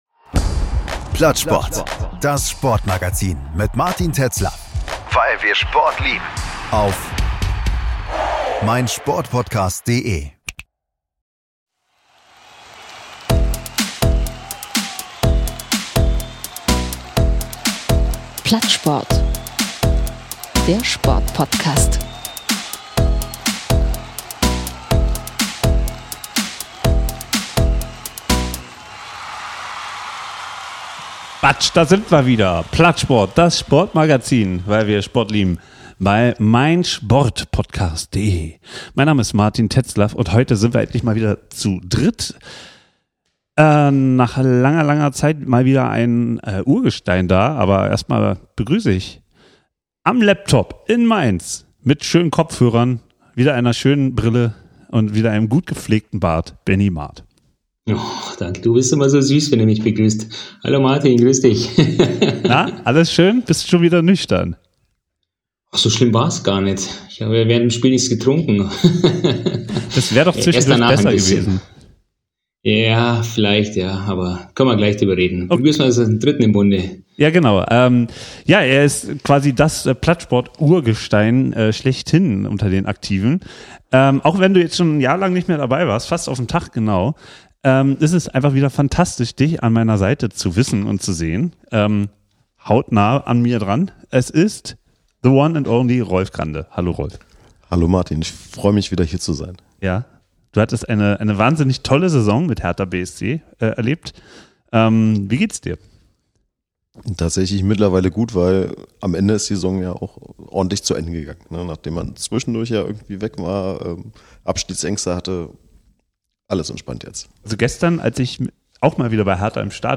Interview Thomas Wark – „Im Brennpunkt des Sports“